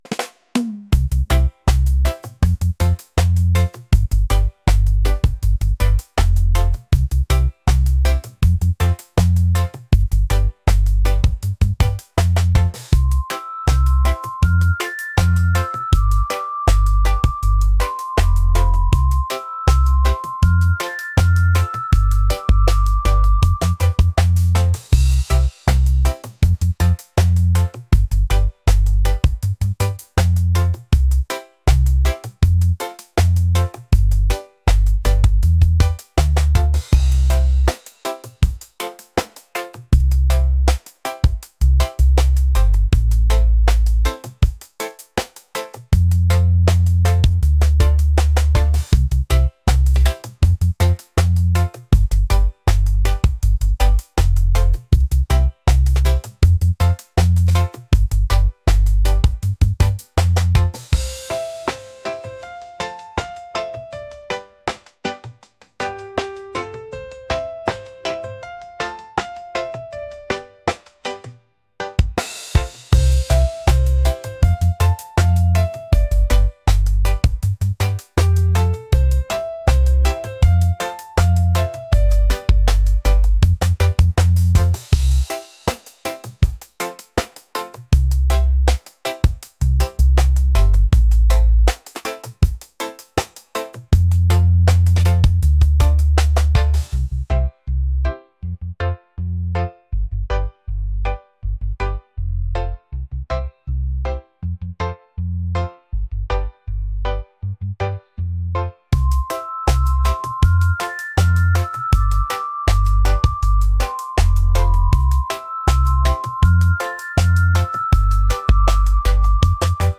reggae | groovy | laid-back